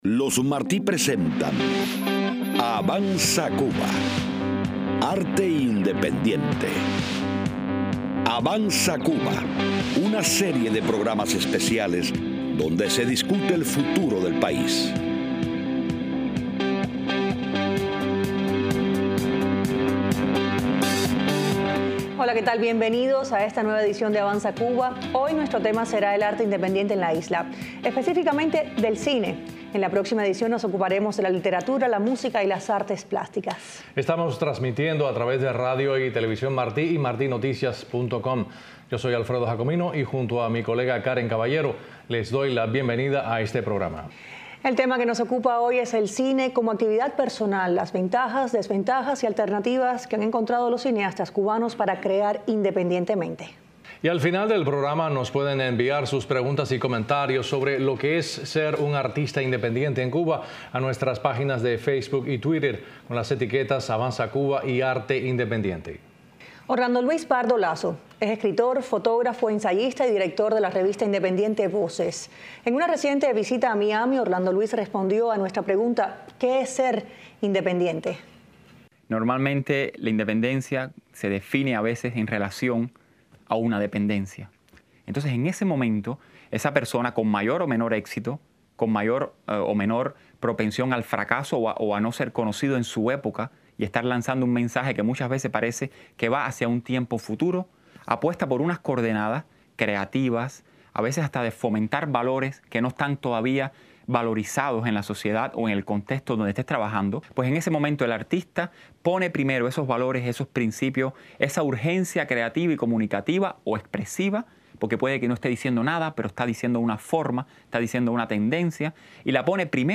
conductores del programa